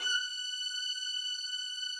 strings_078.wav